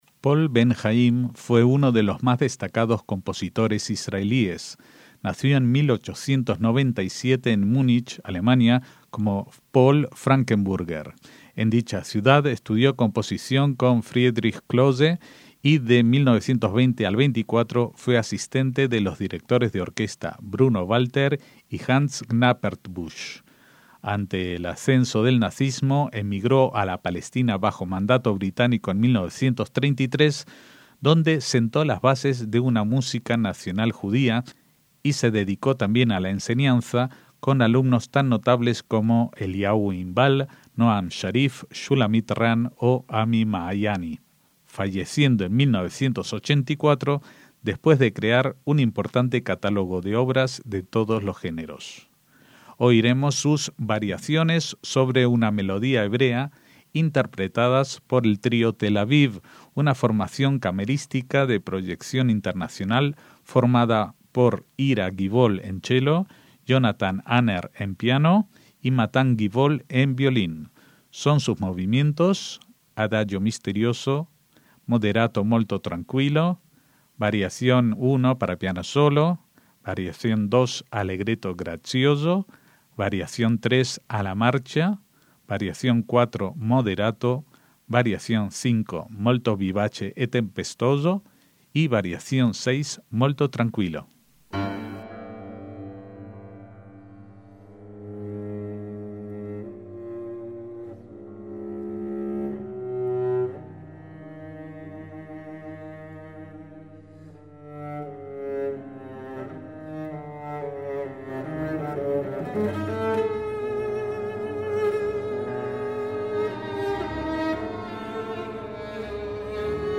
MÚSICA CLÁSICA
trío con piano